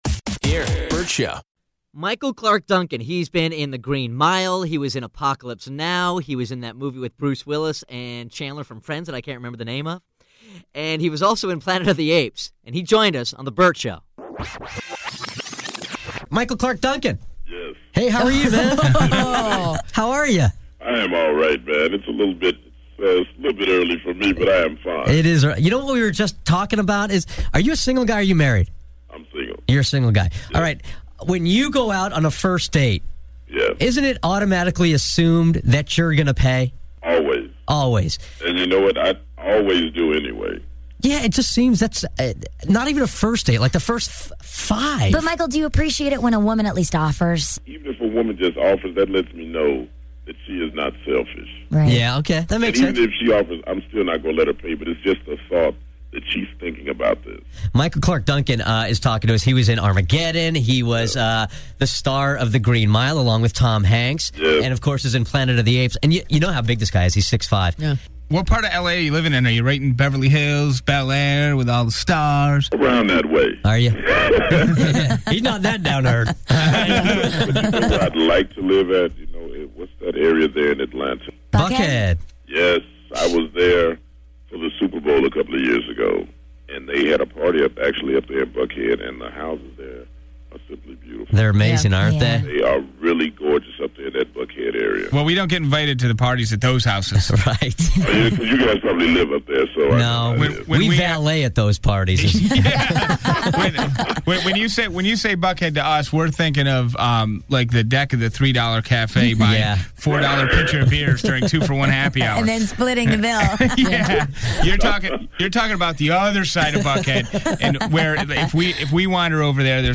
Vault: Interview Michael Clarke Duncan